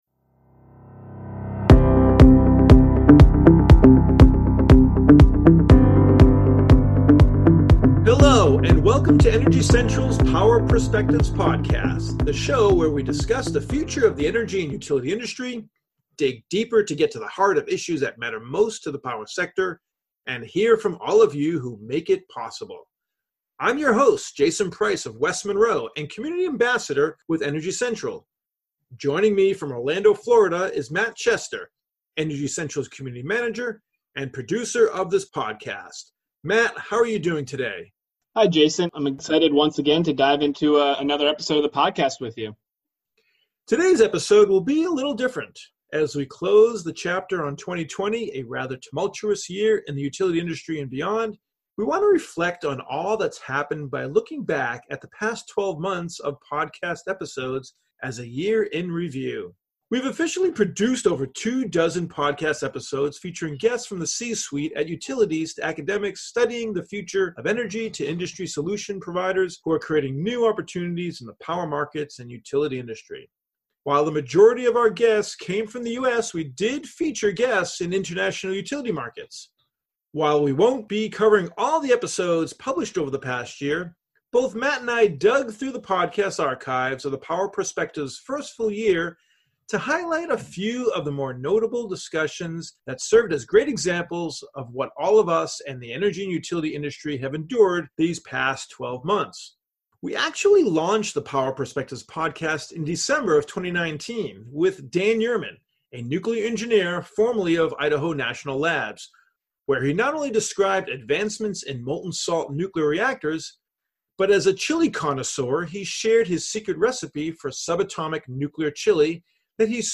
We share updates from some of our fan favorite guests, trade top memories and moments for the podcast, and reflect on what it was like to navigate 2020 in the utility sector (no doubt made more bearable because of the terrific guests who were willing to join us this past year). Take a listen to hear some great moments from episodes you may have missed, learned what news our industry leaders have had to share since then, and to close the chapter on this year as we look ahead to prosperous and engaging 2021.